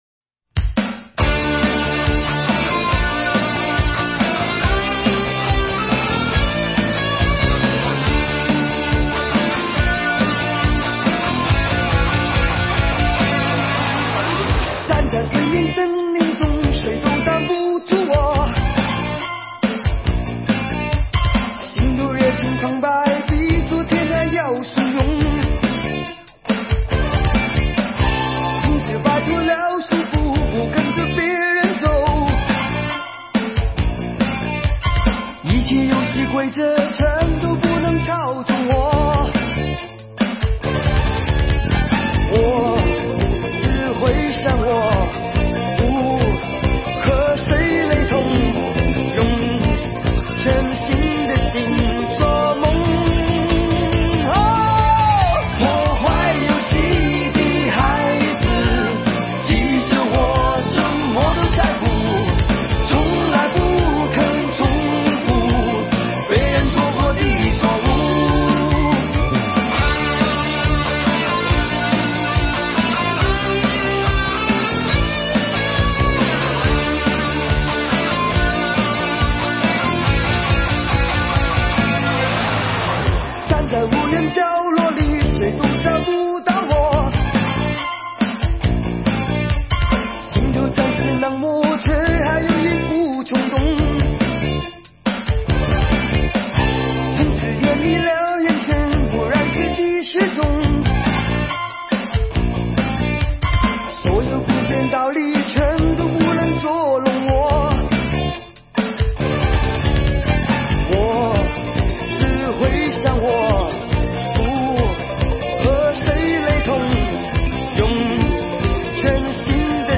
主唱